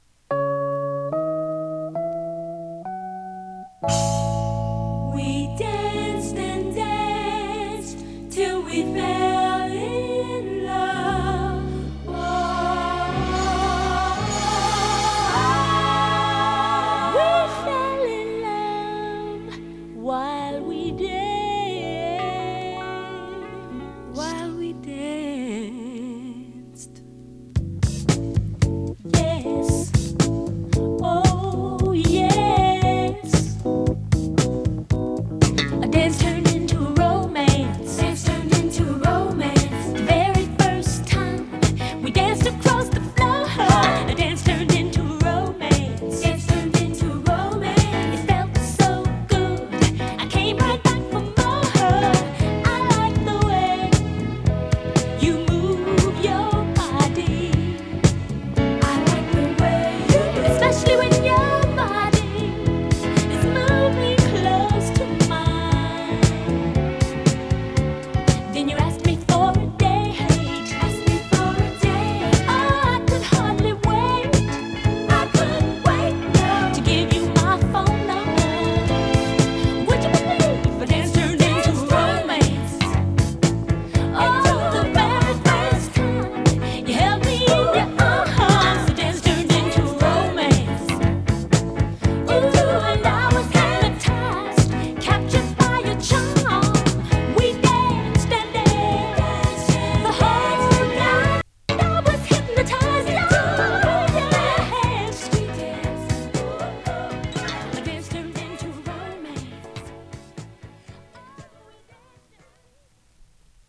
フュージョン ソウル